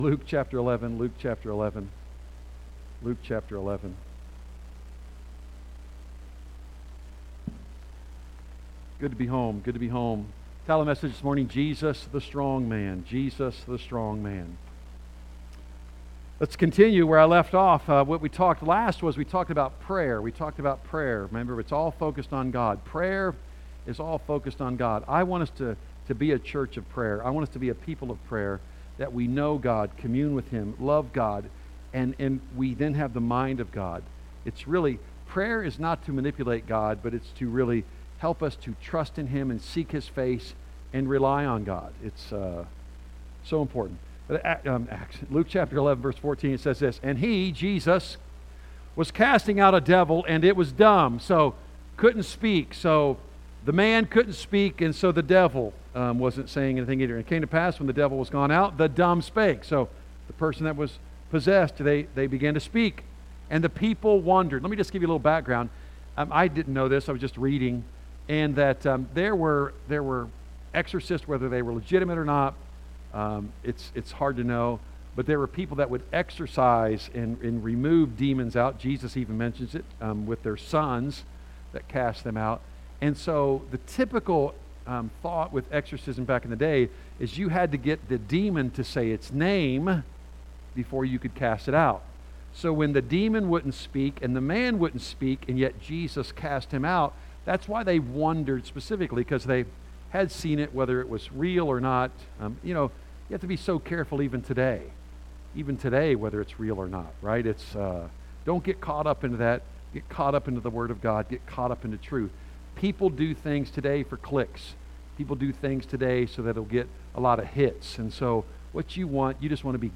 A message from the series "Luke."